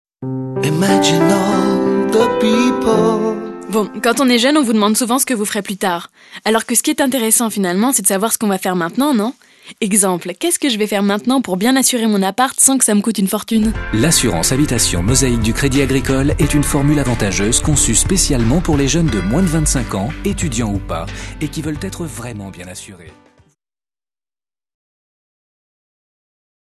Voix reconnaissable typique modulable jeune posée dynamique
Sprechprobe: Werbung (Muttersprache):